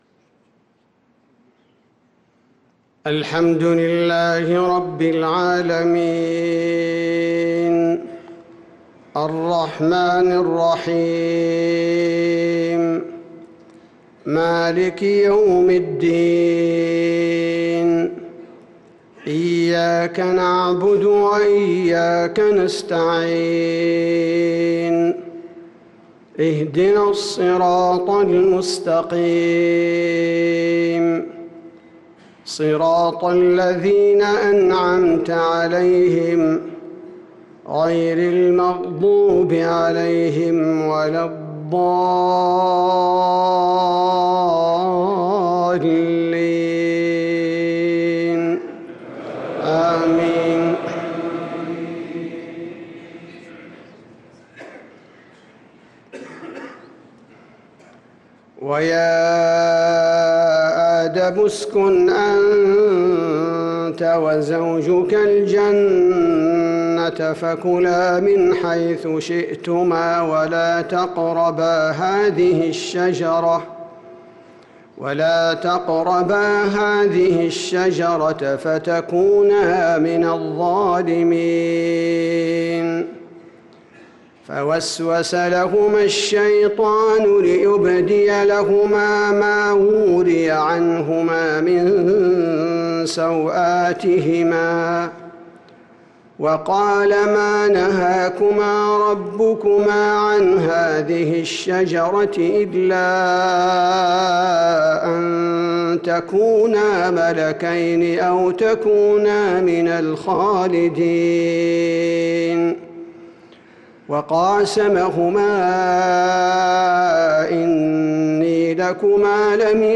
صلاة العشاء للقارئ عبدالباري الثبيتي 24 شعبان 1445 هـ
تِلَاوَات الْحَرَمَيْن .